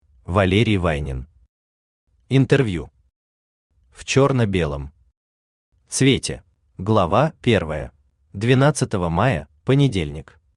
Аудиокнига Интервью в чёрно-белом цвете | Библиотека аудиокниг
Aудиокнига Интервью в чёрно-белом цвете Автор Валерий Вайнин Читает аудиокнигу Авточтец ЛитРес.